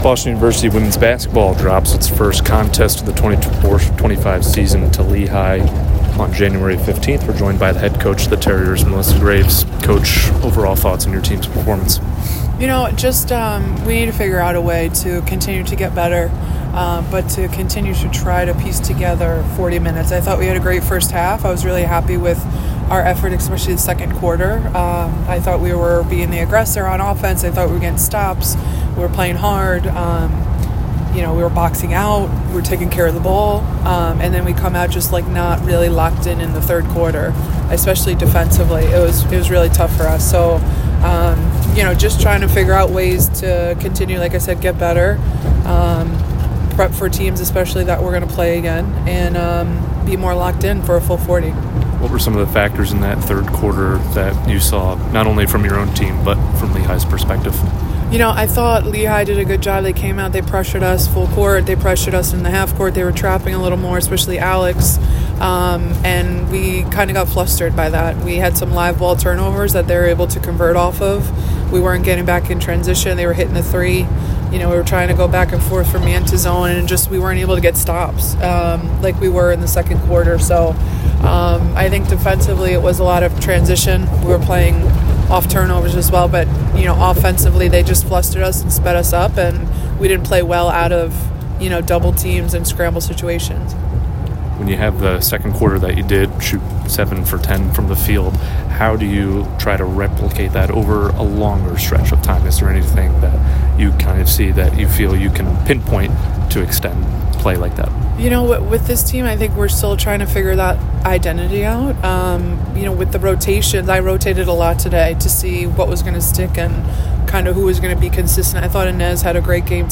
Boston University Athletics
WBB_Lehigh_1_Postgame.mp3